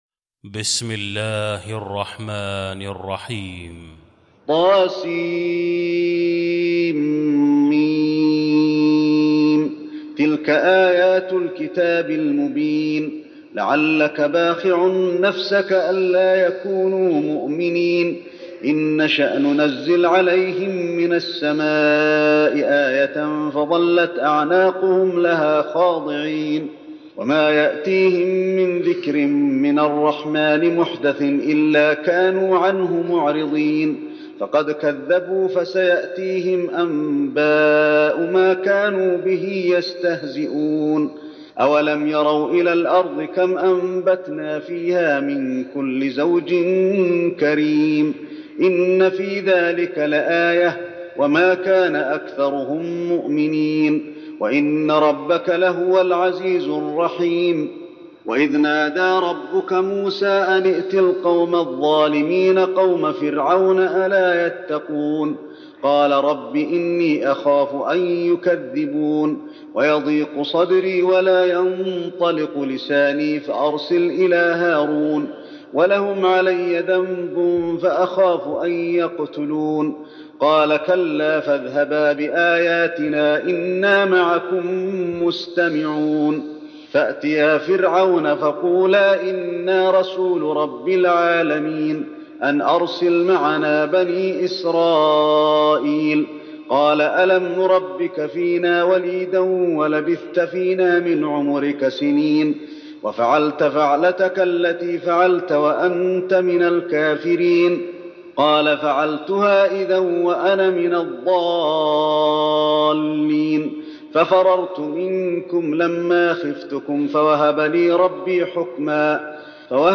المكان: المسجد النبوي الشعراء